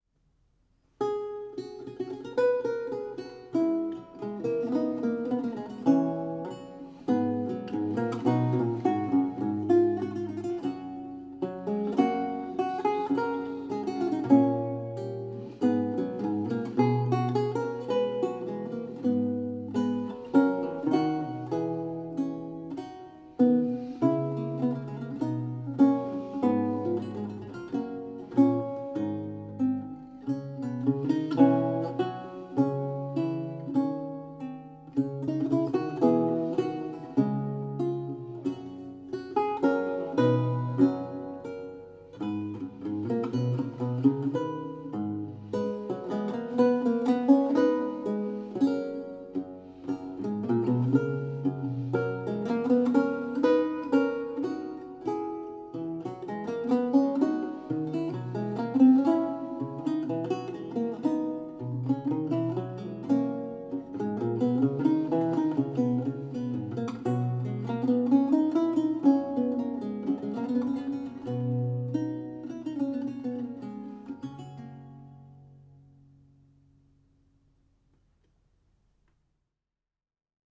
a 16th century lute music piece originally notated in lute tablature
Audio recording of a lute piece from the E-LAUTE project